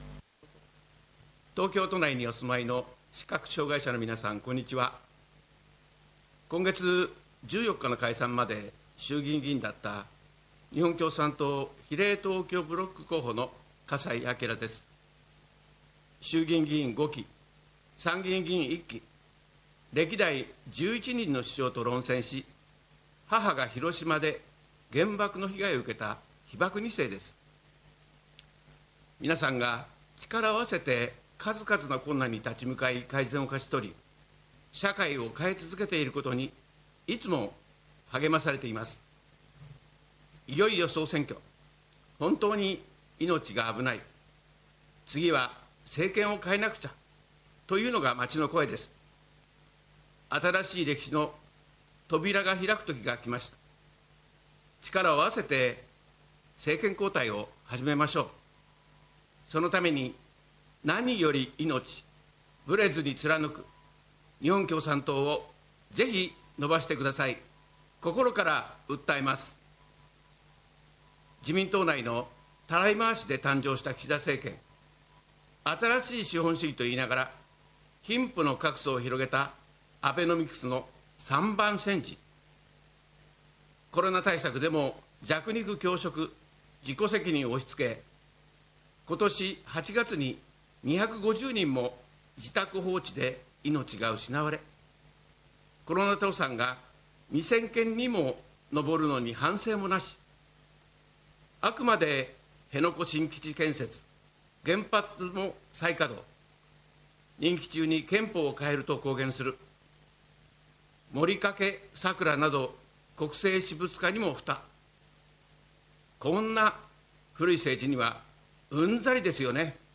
東京の「視覚障害者後援会ニュース」用の訴えをご紹介します。